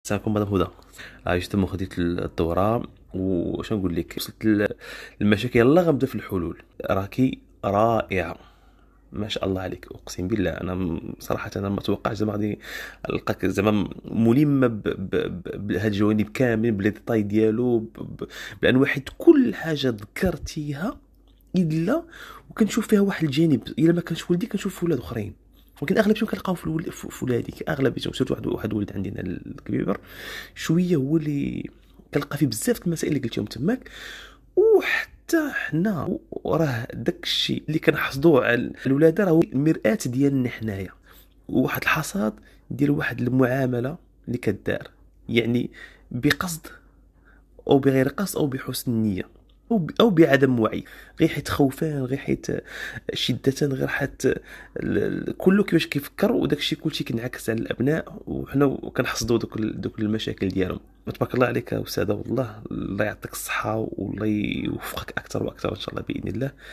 ماذا قال من شارك في الدورة